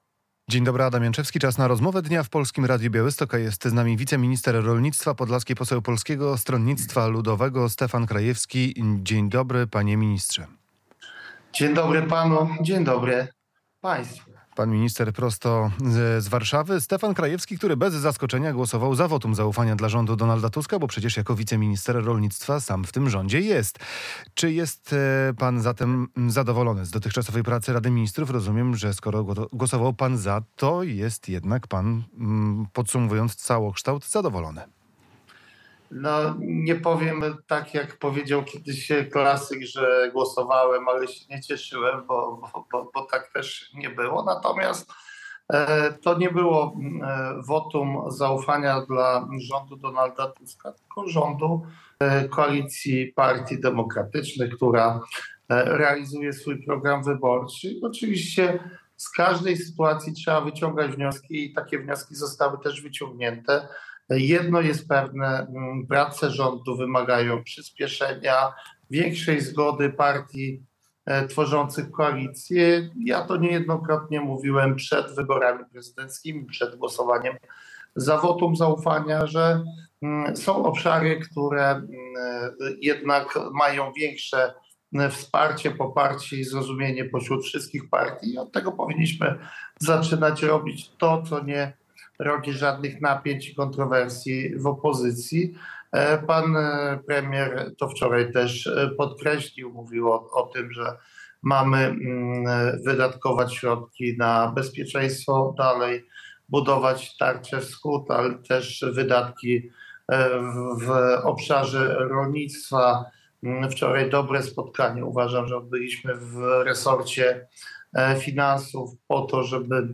Stefan Krajewski - wiceminister rolnictwa, poseł PSL z województwa podlaskiego